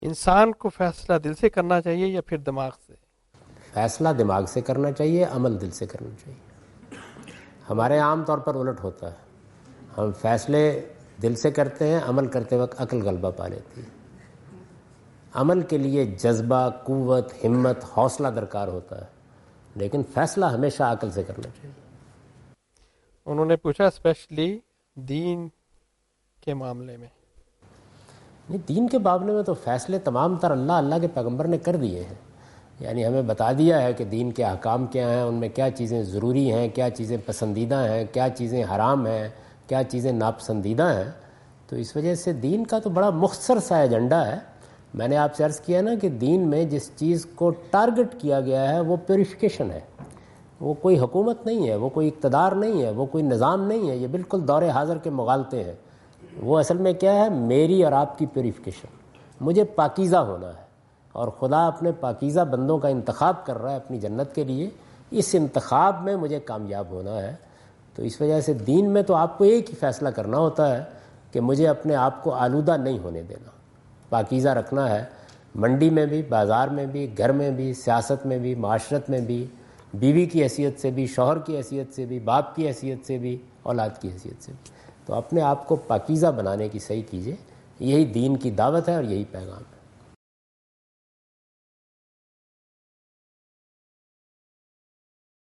Javed Ahmad Ghamidi answer the question about "making decisions with mind or heart?" in Macquarie Theatre, Macquarie University, Sydney Australia on 04th October 2015.
جاوید احمد غامدی اپنے دورہ آسٹریلیا کے دوران سڈنی میں میکوری یونیورسٹی میں "دینی معاملات میں فیصلہ دل سے کریں یا دماغ سے؟" سے متعلق ایک سوال کا جواب دے رہے ہیں۔